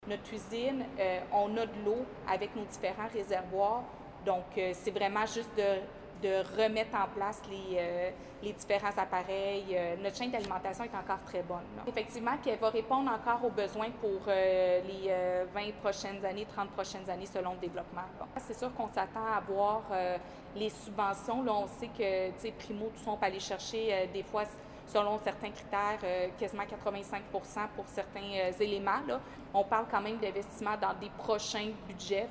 Nouvelles